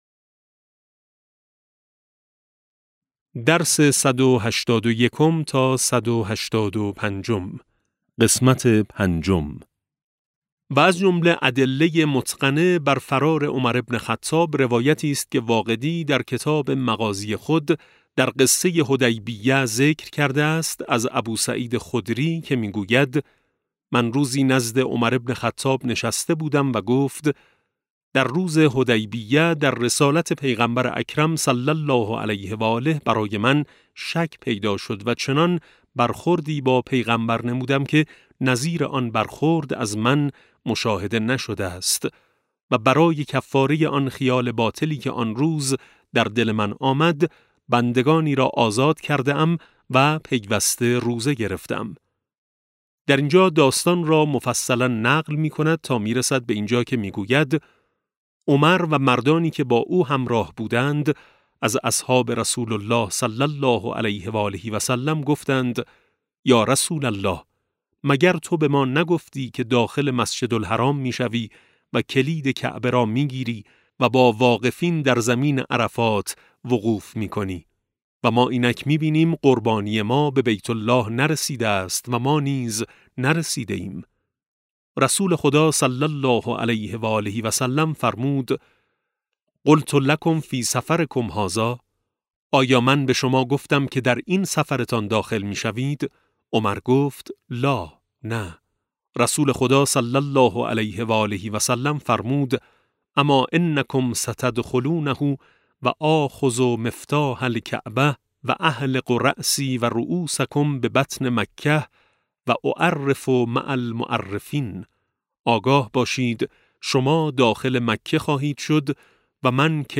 کتاب صوتی امام شناسی ج 13 - جلسه5